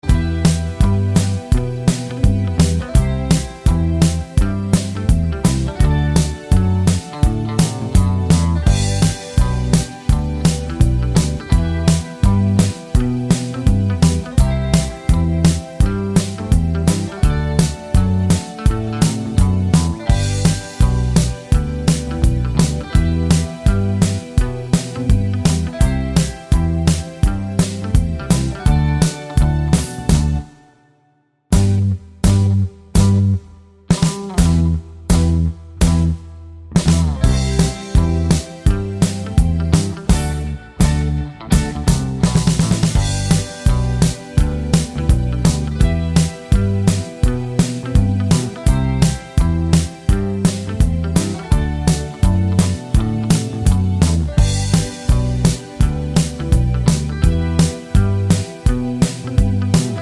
no Backing Vocals Rock 'n' Roll 2:13 Buy £1.50